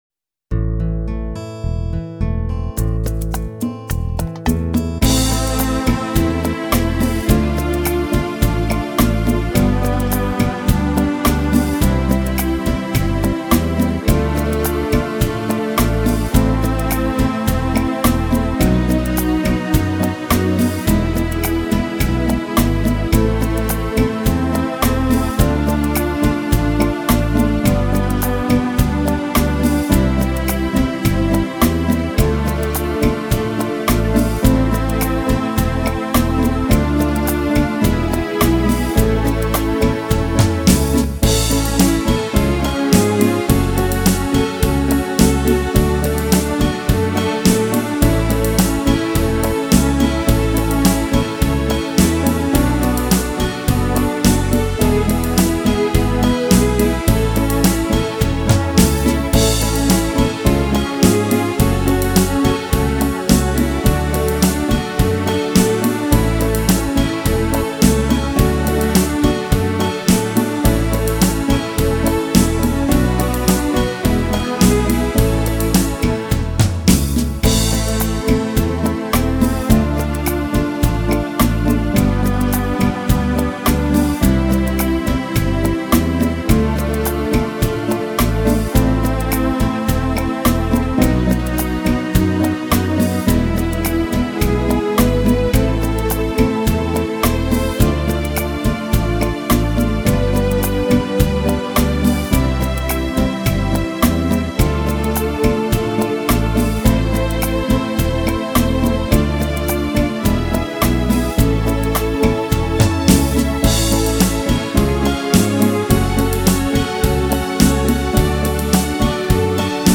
Boléro